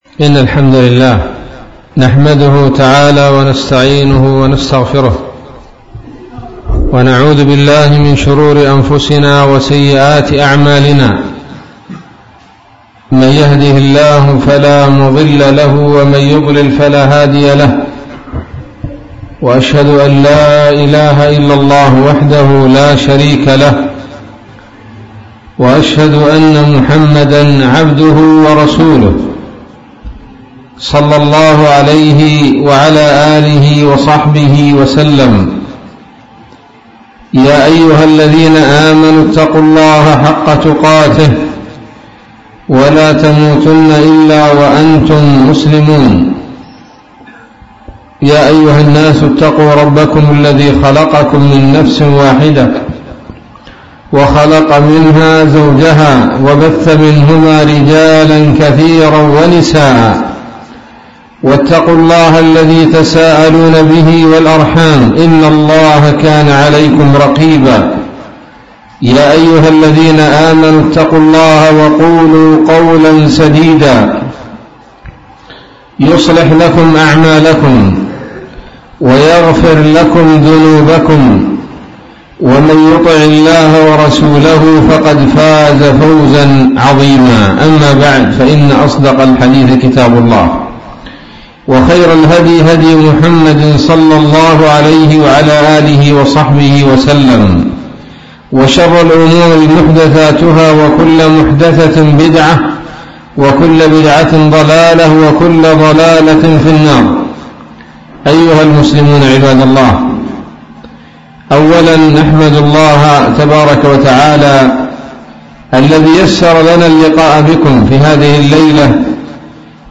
محاضرة قيمة بعنوان:((تعرف على دعوة محمد صلى الله عليه وسلم